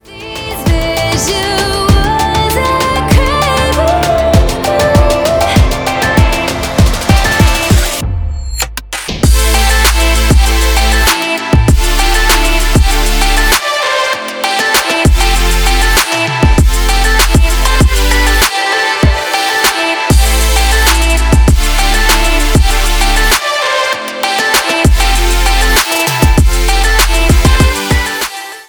edm , трэп